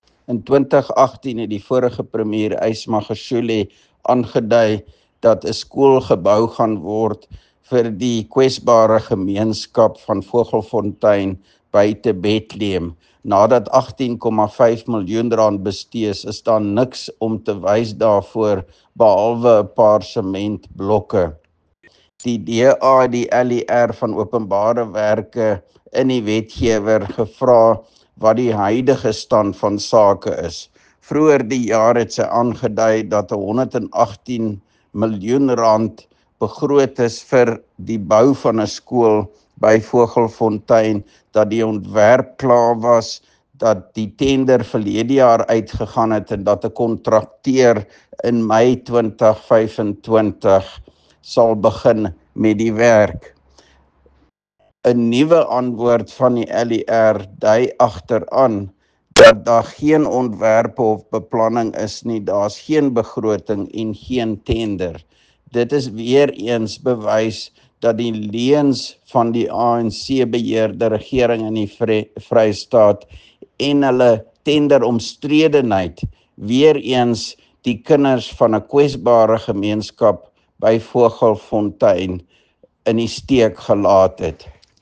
Afrikaans soundbites by Roy Jankielsohn MPL and